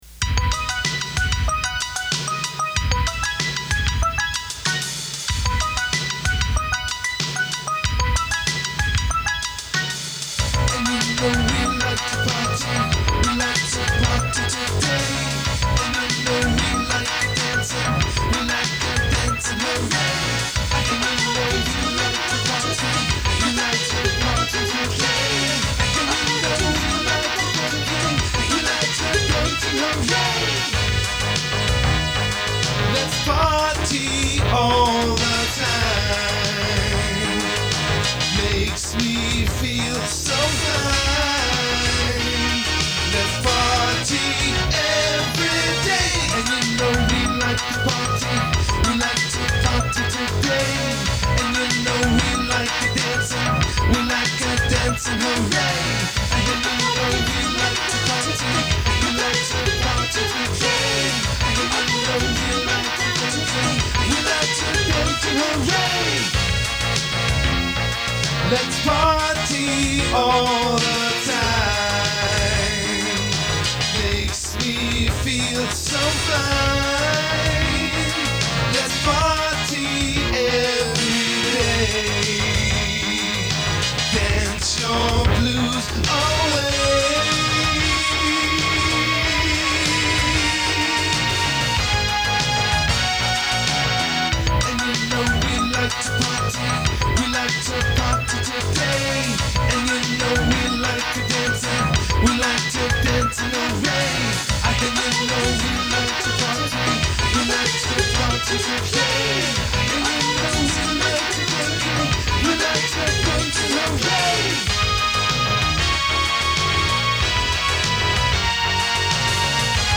MIDI and sampling. Using the Ensoniq Mirage keyboard, 8 bit. Drum machine was the TR 707. Using a Roland Juno 106. I’m sure there is a Casio involved. Has a bit of a funk latin feel.